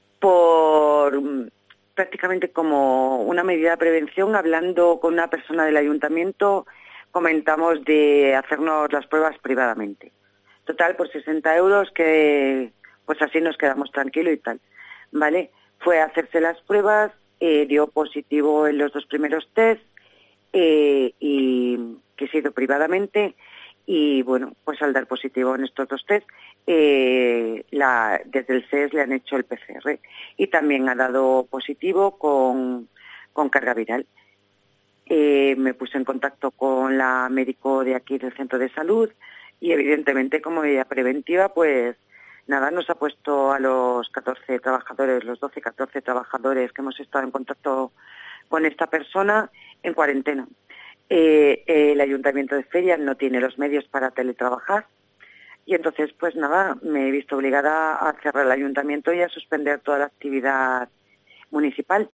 Manuela Cornejo, alcaldesa de Feria (Badajoz)